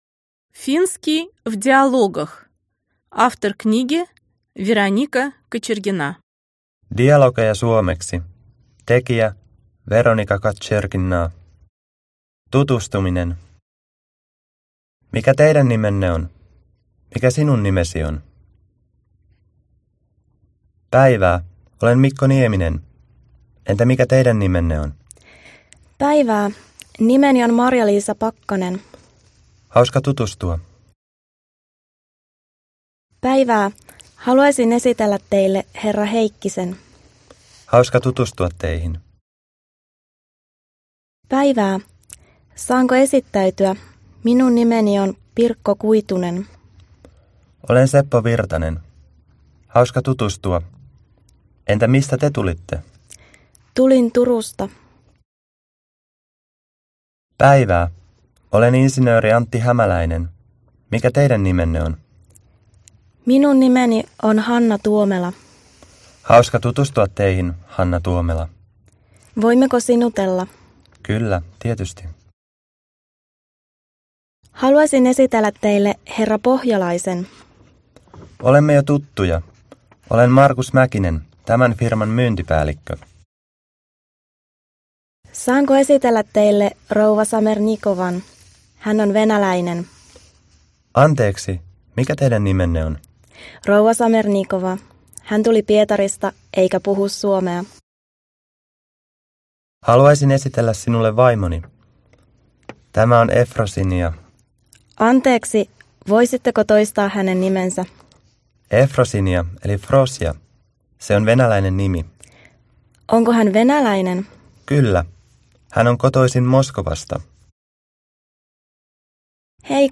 Аудиокнига Финский в диалогах | Библиотека аудиокниг